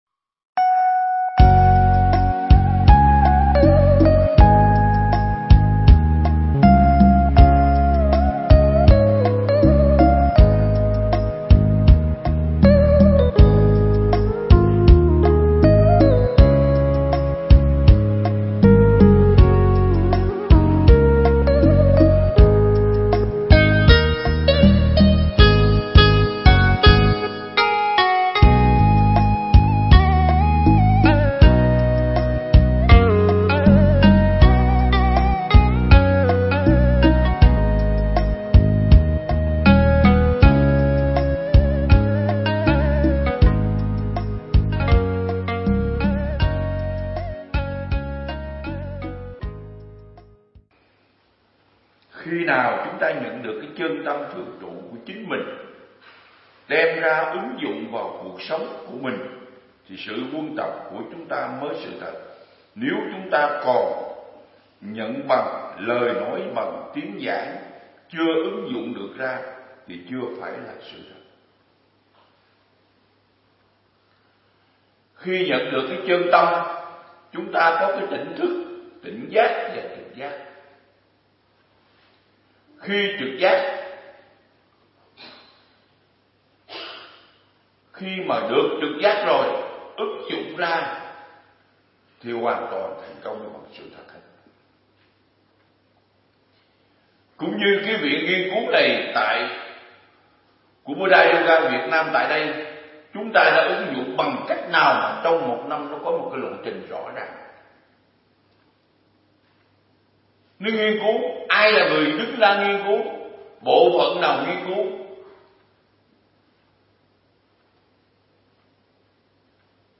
Mp3 Thuyết Giảng Triết Lý Thủ Lăng Nghiêm Phần 9